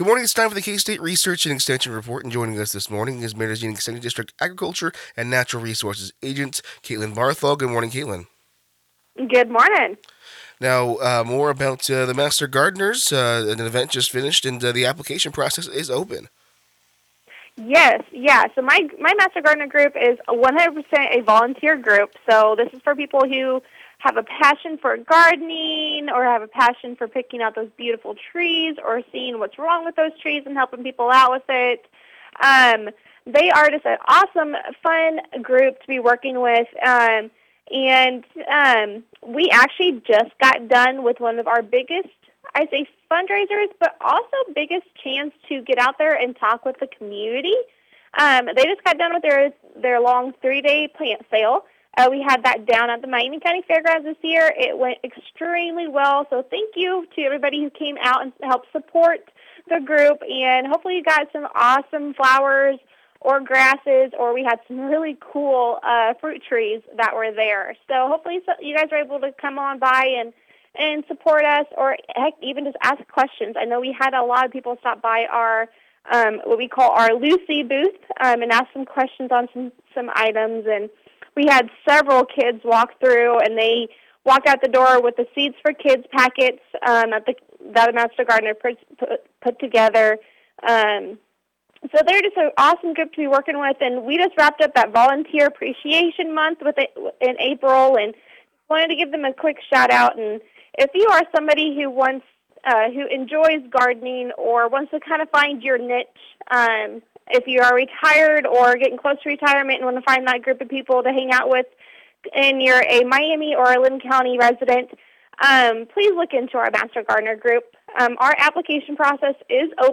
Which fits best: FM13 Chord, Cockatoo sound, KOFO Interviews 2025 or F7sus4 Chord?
KOFO Interviews 2025